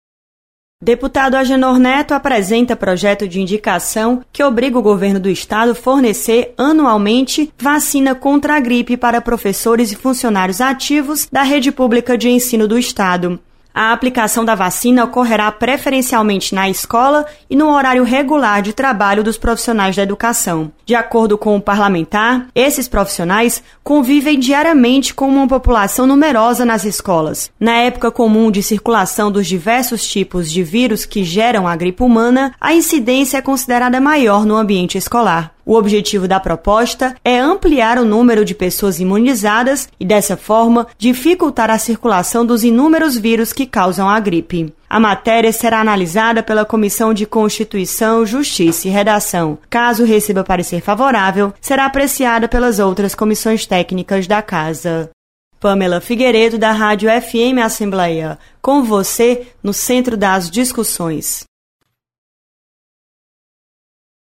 Projeto beneficia professores e servidores da educação. Repórter